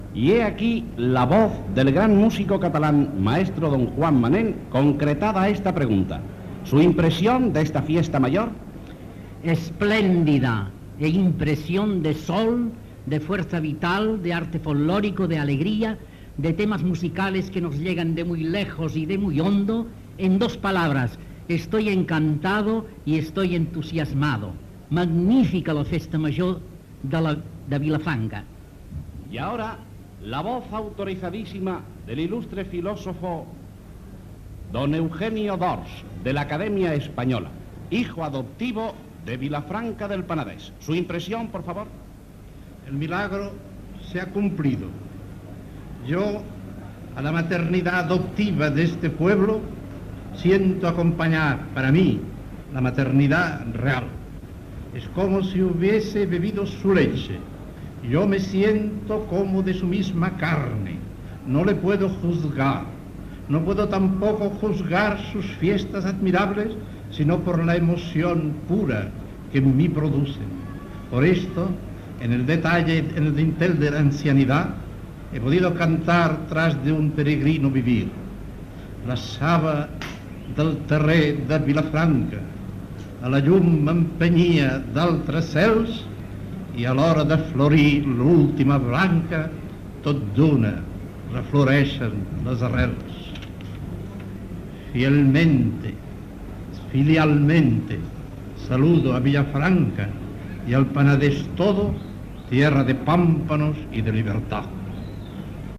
Entrevista al músic Joan Manén i al pensador Eugenio d'Ors, nomenat fill adoptiu de la localitat l'any 1944, a les Festes de Sant Fèlix de Vilafranca del Penedès del 1948
Informatiu